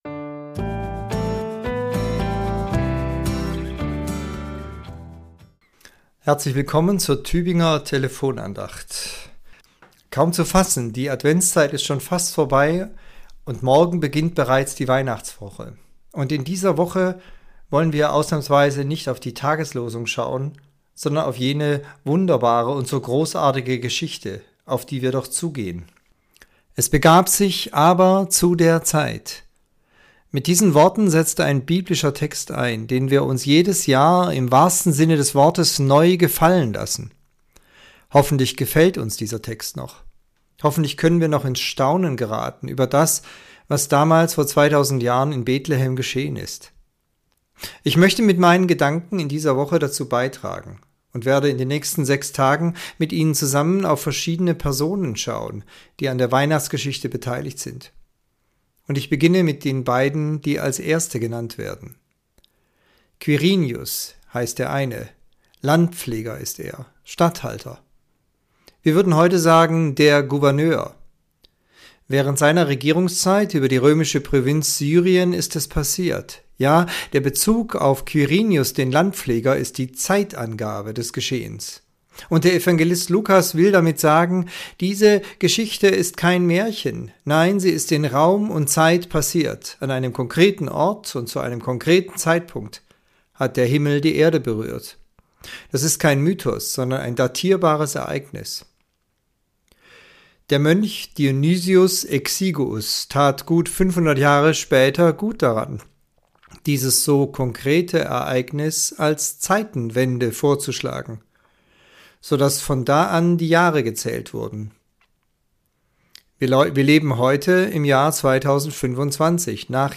Andacht zur Weihnachtswoche - Teil 1